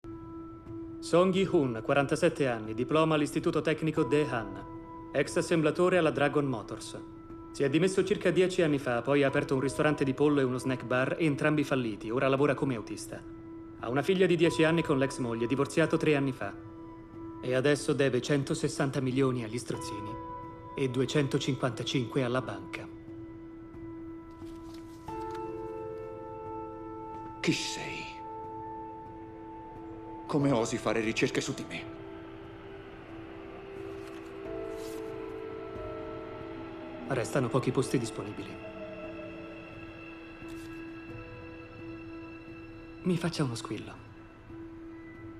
nel telefilm "Squid Game", in cui doppia Gong Yoo.
Dal 2014 studia canto lirico da baritono leggero.